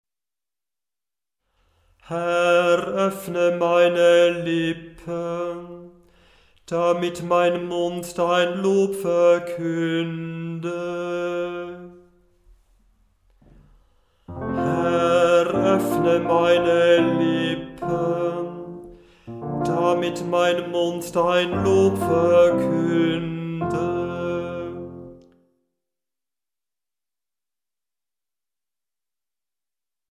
Beim Stundengebet übernimmt nach der Einleitung ein Kantor/eine Kantorin das Anstimmen der Gesänge.
Beim Stundengebet selber wird der Ruf natürlich nur einmal gesungen, die mehrmaligen Wiederholunge mögen das Üben erleichtern.
Herr, öffne meine Lippen, Gl 614,1 736 KB Erster Ton f, eine große Terz tiefer